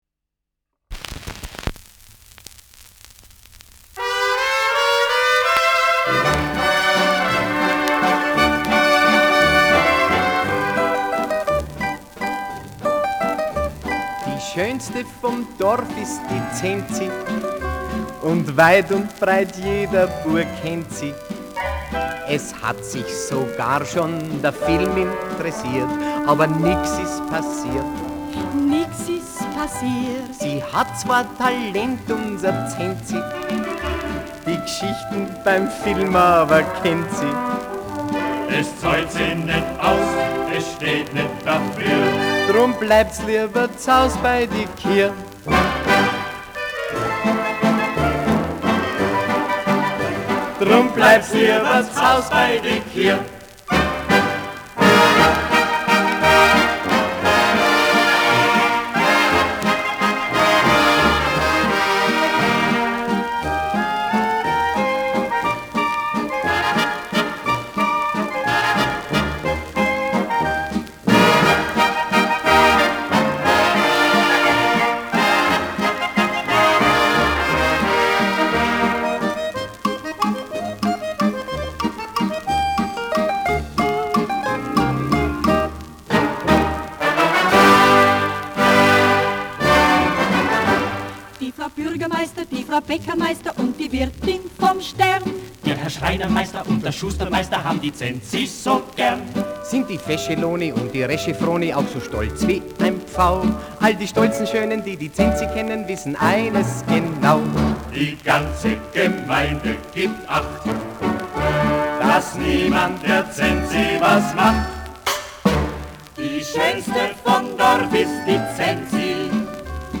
Schellackplatte
Durchgehend leichtes Knistern : Vereinzelt leichtes Knacken